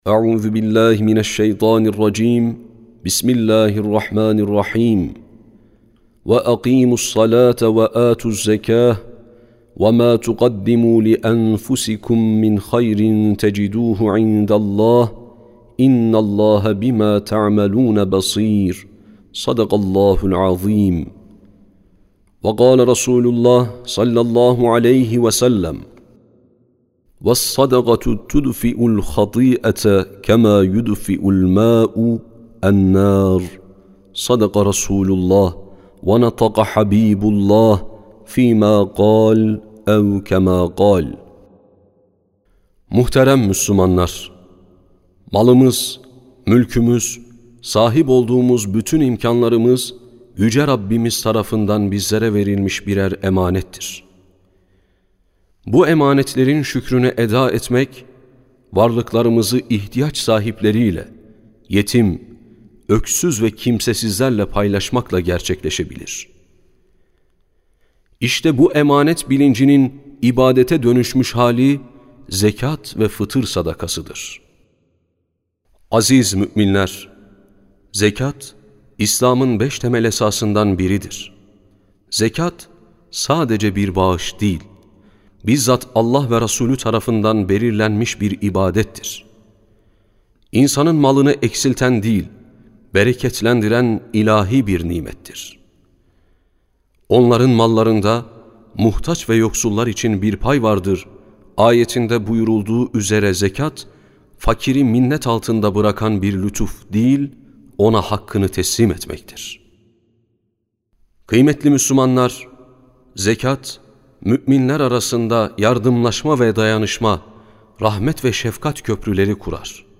Sesli Hutbe (Zekat ve Fıtır Sadakası).mp3